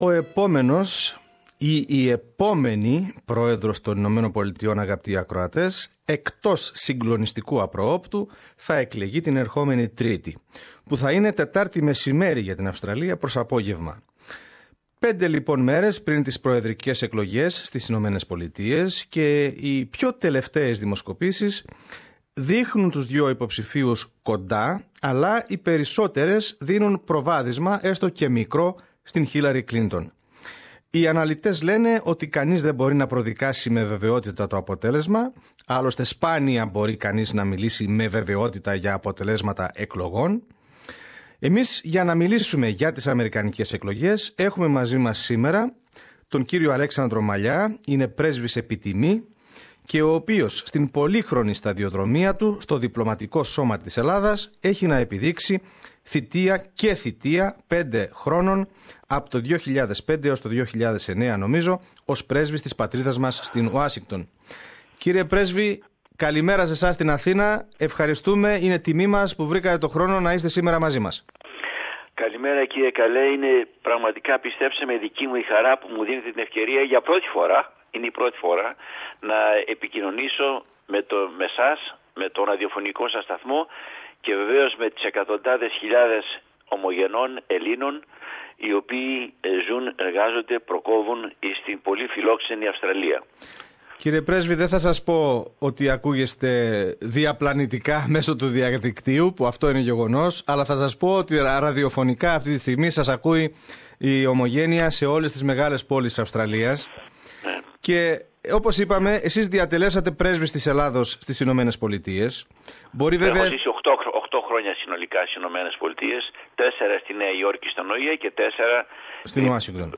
Για να μιλήσουμε όμως για τις αμερικανικές εκλογές έχουμε μαζί μας τον κ. Αλέξανδρος Μαλλιά, πρέσβη επί τιμή, ο οποίος στην πολύχρονη σταδιοδρομία του στο διπλωματικό σώμα της Ελλάδας έχει να επιδείξει και θητεία πέντε χρόνων, από το 2005 έως το 2009, ως πρέσβης της πατρίδας μας στην Ουάσιγκτον.